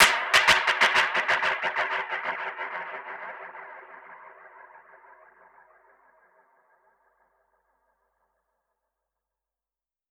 Index of /musicradar/dub-percussion-samples/95bpm
DPFX_PercHit_B_95-03.wav